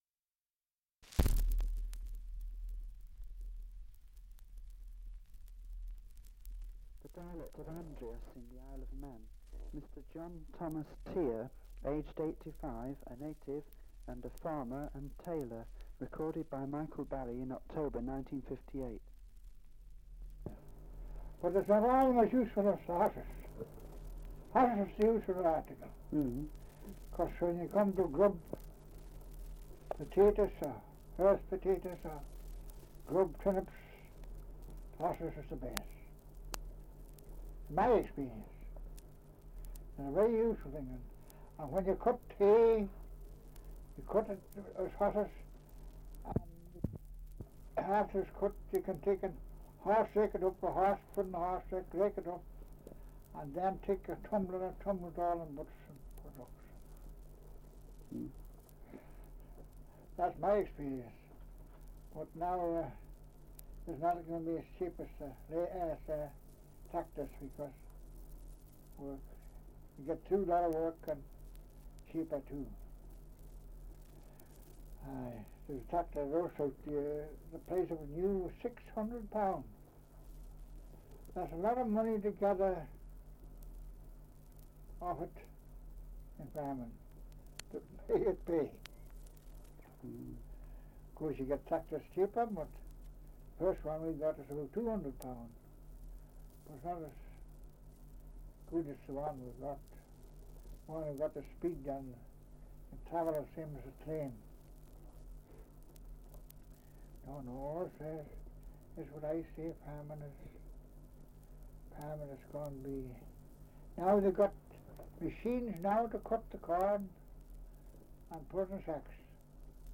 Survey of English Dialects recording in Andreas, Isle of Man
This is an edited version of an interview which is available in its complete form at C908/10 C5 (British Library reference number).
78 r.p.m., cellulose nitrate on aluminium